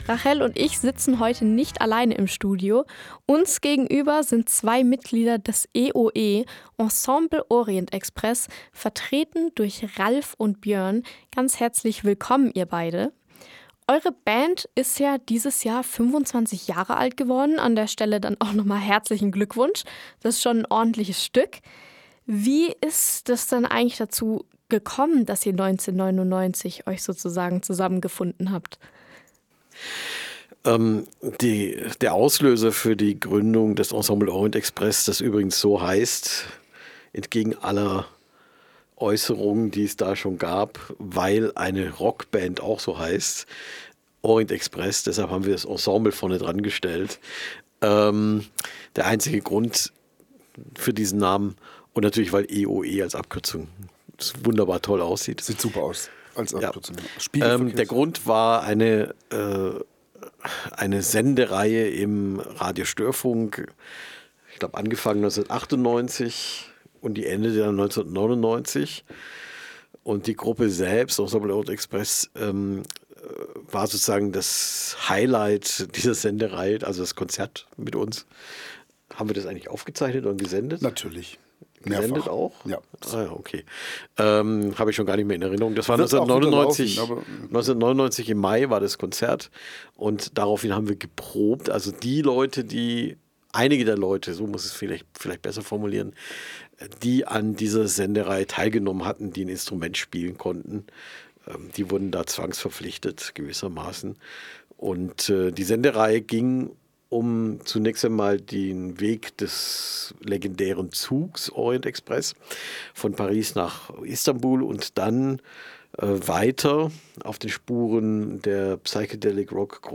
Im Interview mit uns blicken die beiden auf die Geschichte der Band zurück.